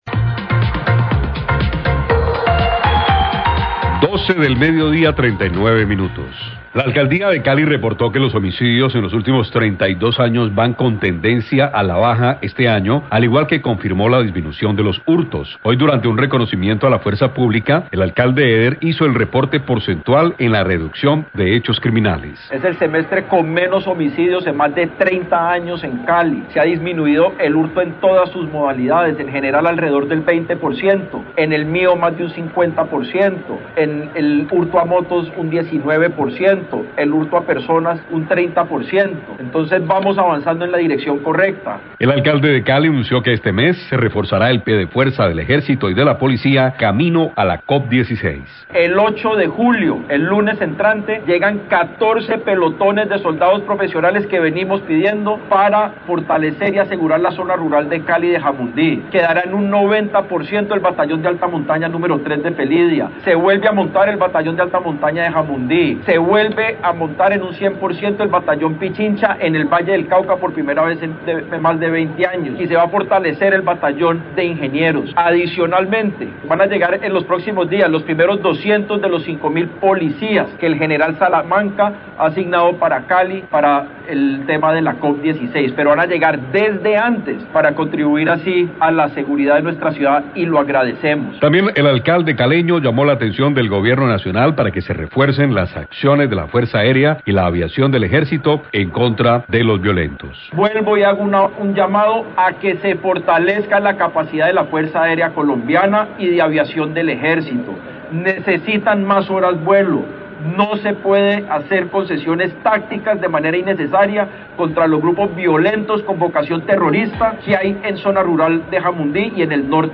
Alcalde de Cali, Alejandro Eder, durante evento de reconocimiento a la fuerza pública destacó la reducción de los homicidios y hurtos en la ciudad.